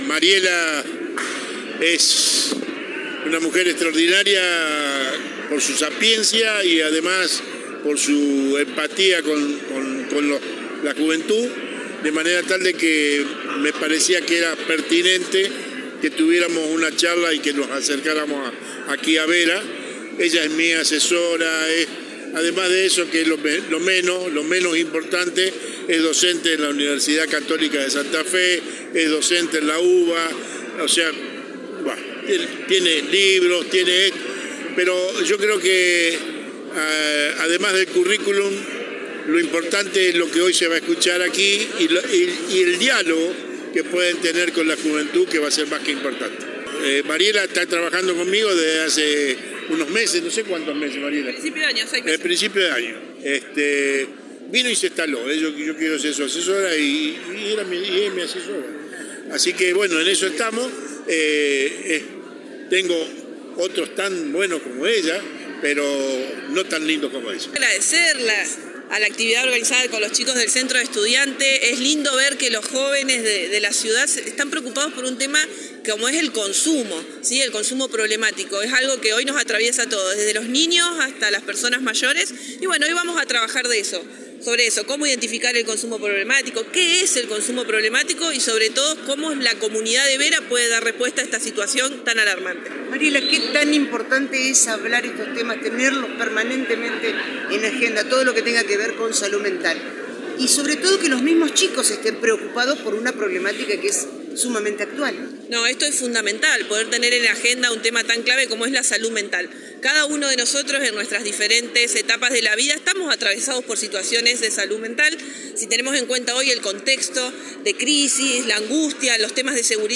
Charla para los jóvenes del secundario
La misma se llevó a cabo en la Sociedad Italiana.